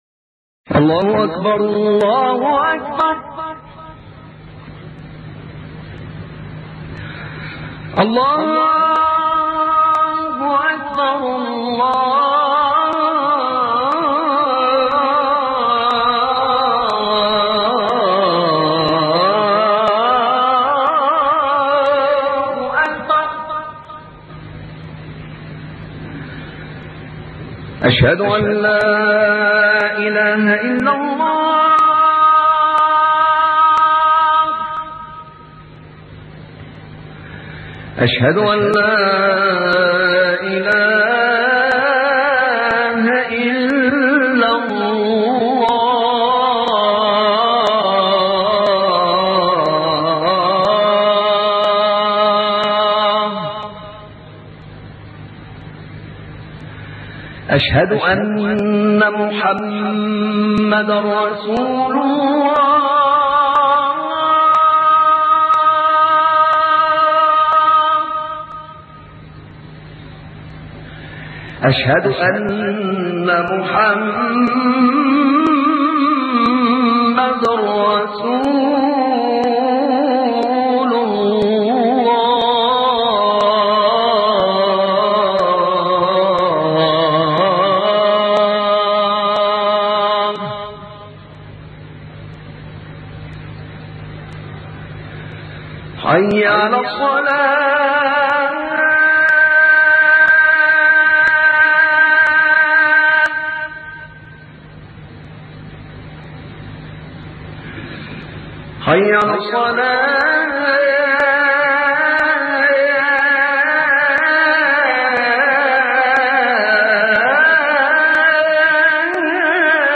أذان القارئ الشيخ أحمد نعينع